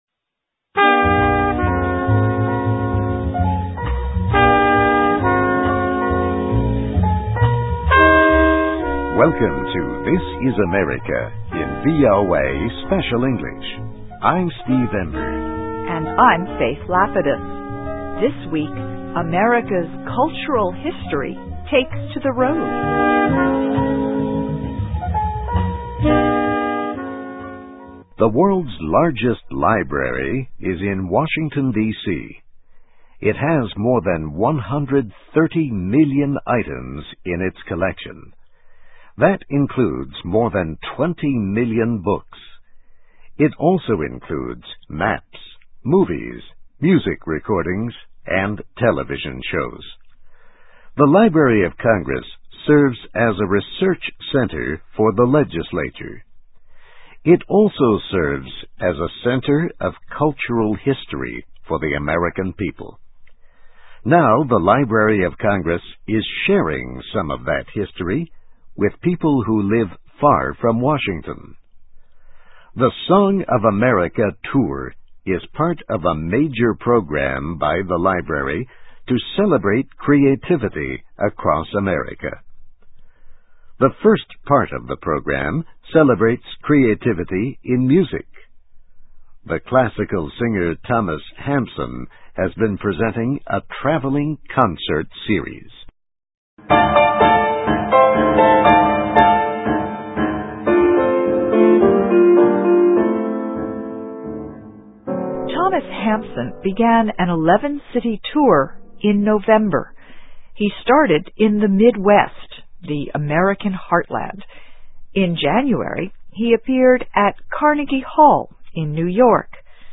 USA: Library of Congress Presents 'Song of America' Tour (VOA Special English 2006-03-26)<meta name="description" content="Text and MP3 File.
Listen and Read Along - Text with Audio - For ESL Students - For Learning English